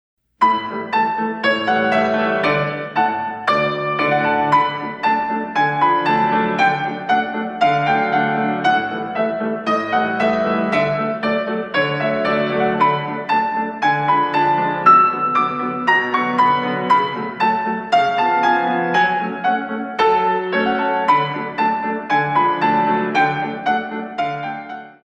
32 Counts